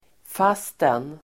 Uttal: [f'as:ten]